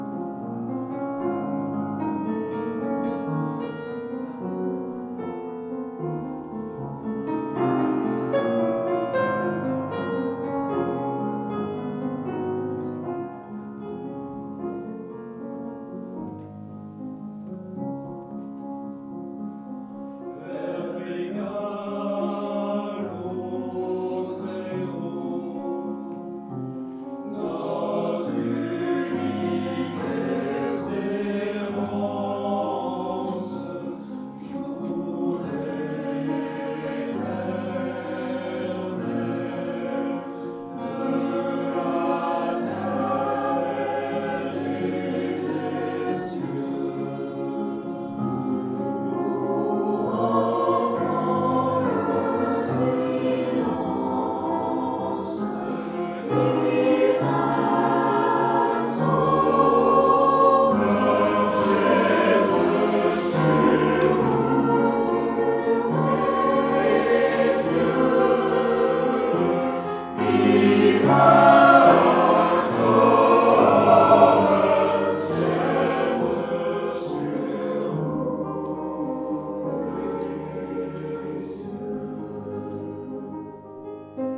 Concerts du 24 mai 2024 au Temple de Rambouillet et du 25 mai 2024 en l’église St Nicolas de Saint Arnoult en Yvelines